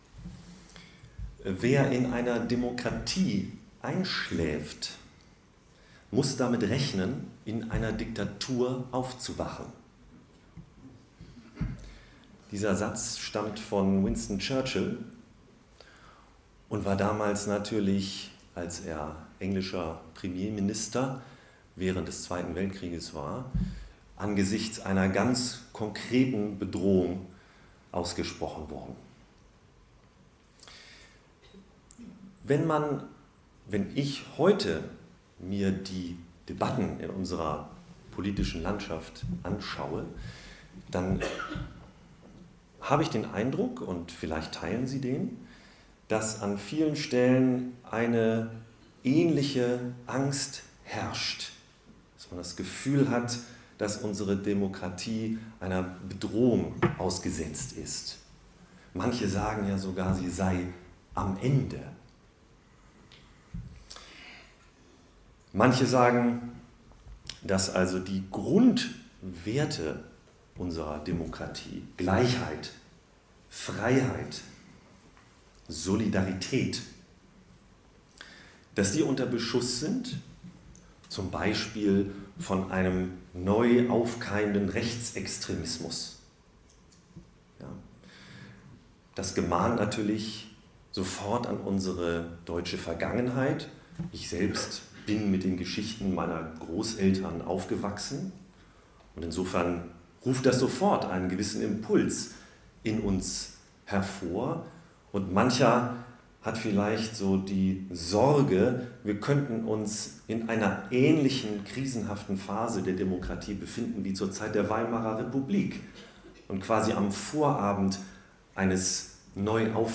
(Die Aufnahmen sind Vortragsmitschnitte aus dem Jahr 2021 aus Athen...)